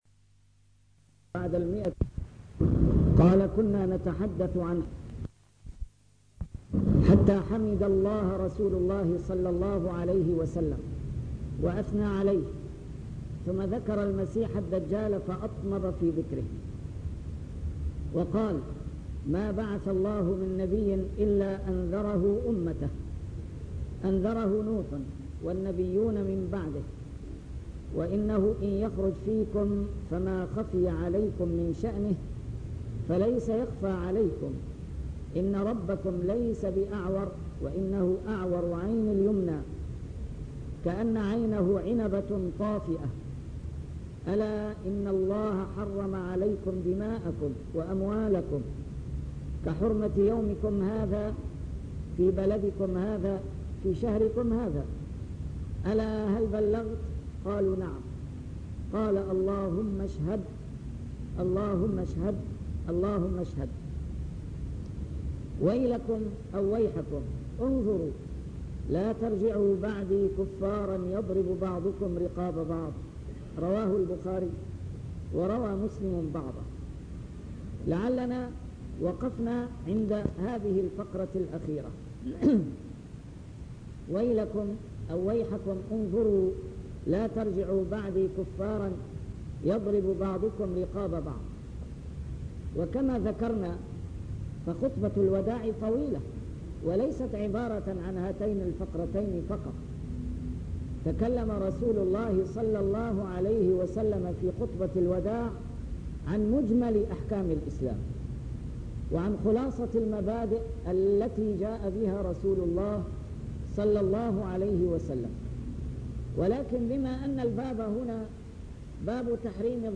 A MARTYR SCHOLAR: IMAM MUHAMMAD SAEED RAMADAN AL-BOUTI - الدروس العلمية - شرح كتاب رياض الصالحين - 308- شرح رياض الصالحين: تحريم الظلم